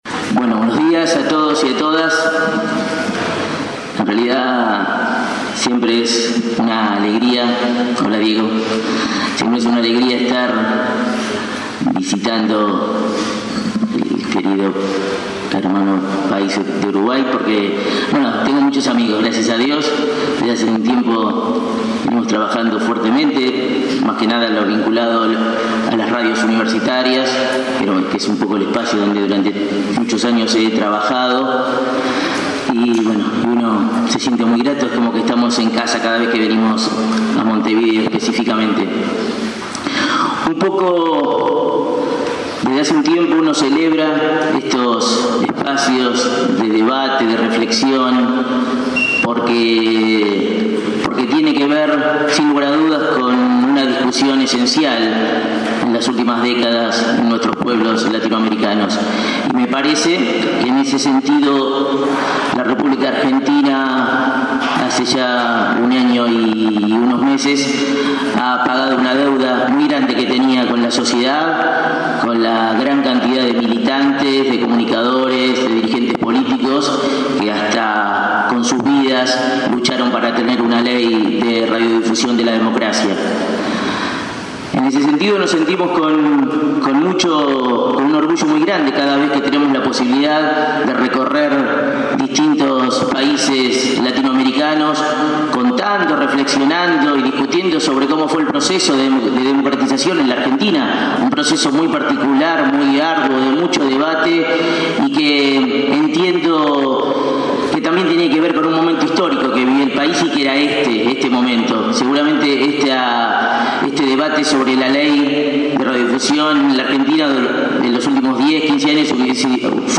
Para cerrar el ciclo de audios del Encuentro Regional por una Comunicación Democrática que hemos venido ofreciendo en nuestra web, en esta oportunidad les brindamos la palabra de los representantes de la República Argentina.
realizada el pasado 30 de setiembre en el paraninfo de la Univresidad de la República